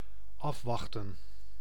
Ääntäminen
IPA: /ˈɑfˌʋɑxtə(n)/